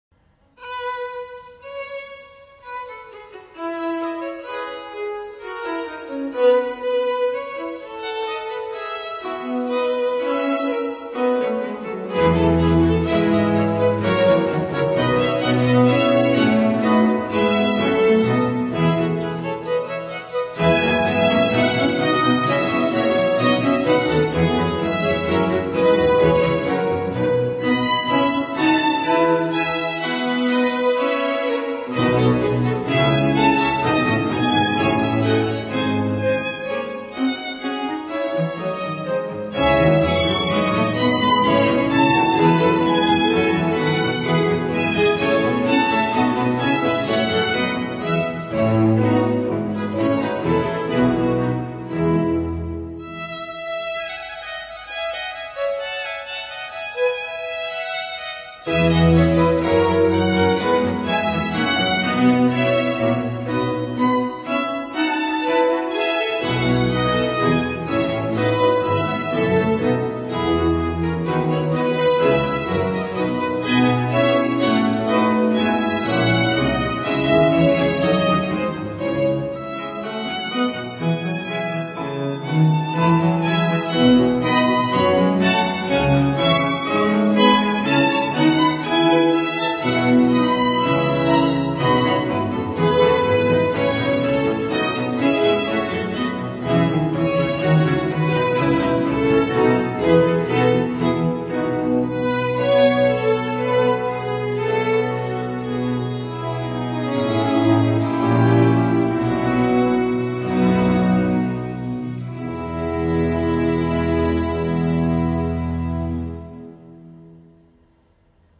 CLASSIC